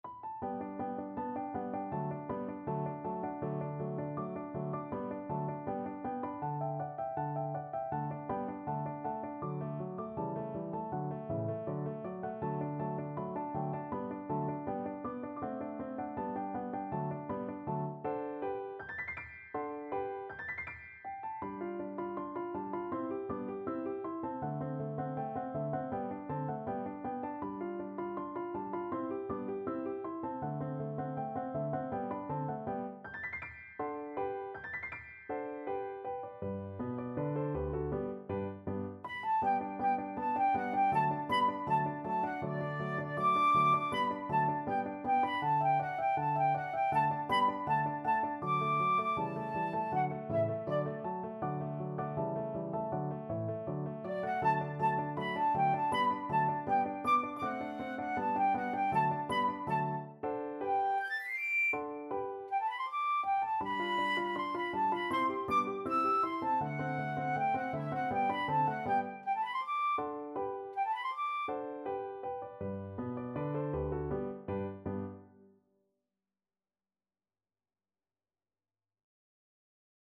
Andante
2/4 (View more 2/4 Music)
Classical (View more Classical Flute Music)